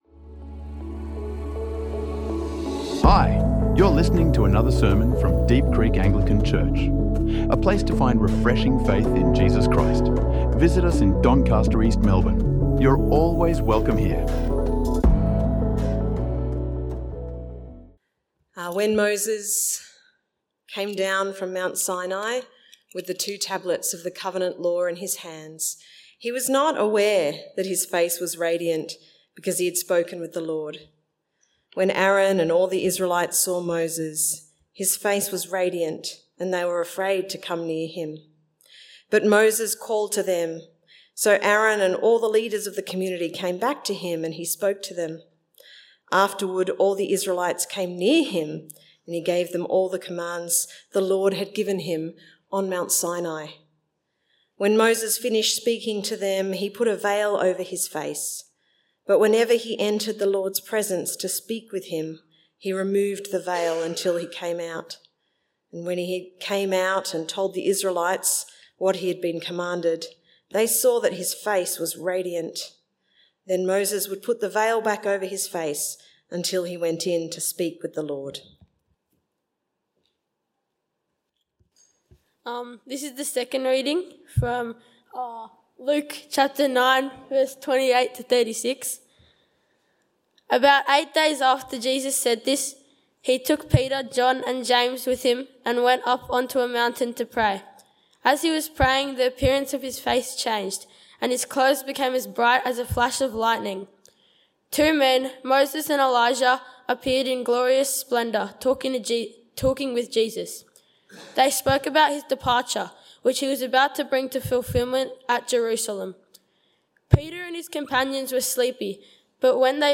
A powerful sermon on Jesus’ Transfiguration, baptism, and what it means to listen to God’s chosen Son in a world that needs hope.